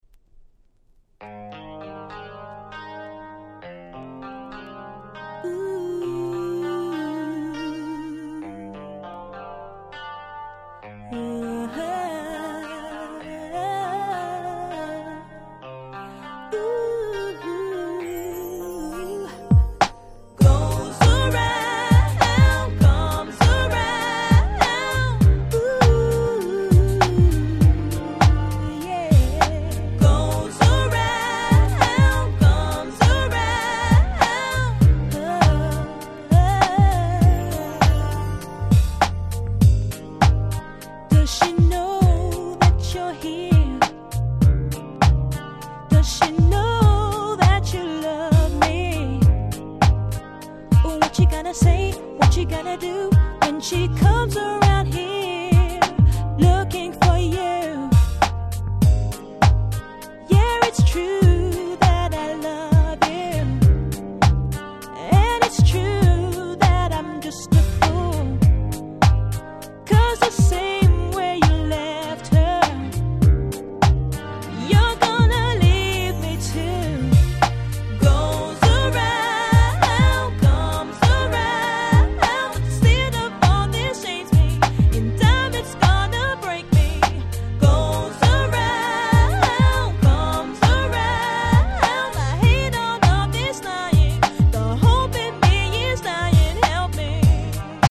02' Nice R&B !!